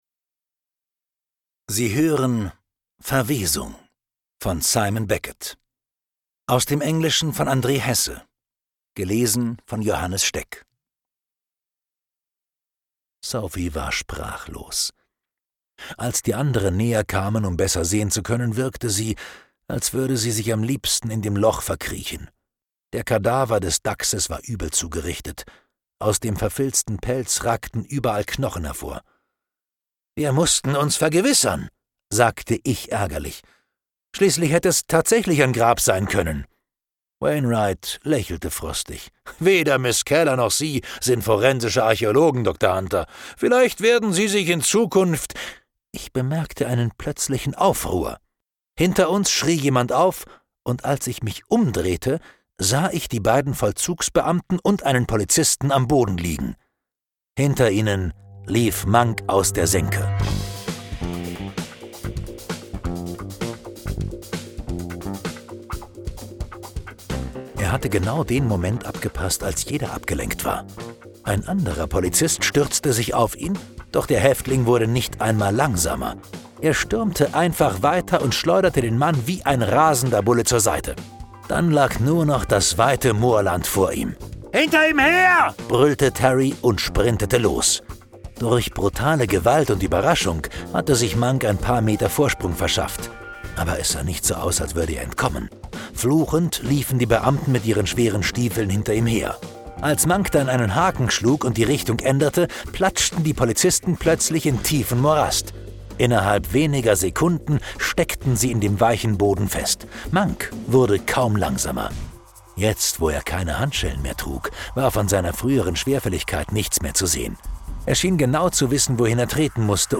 2012 | 6. Auflage, Gekürzte Ausgabe